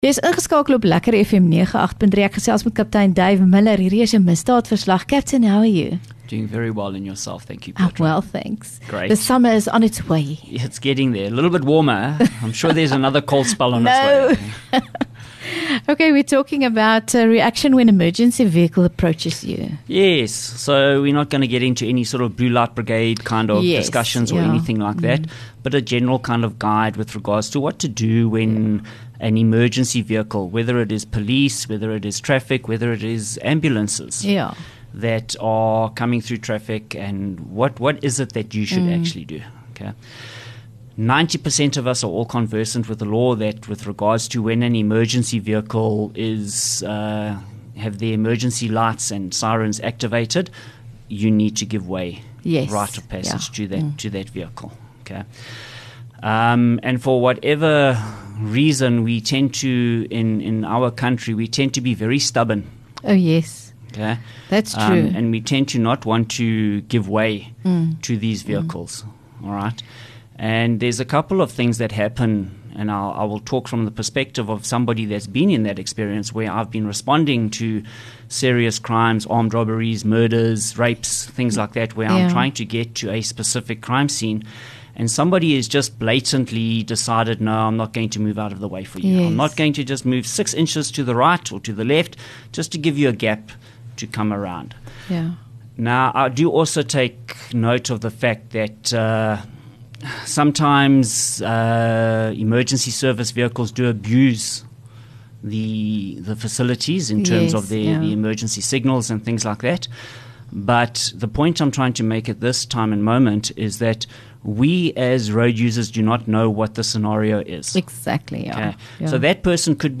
LEKKER FM | Onderhoude 8 Aug Misdaadverslag